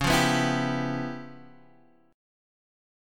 Db7#9b5 chord